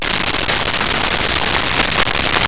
Applause Short
APPLAUSE SHORT.wav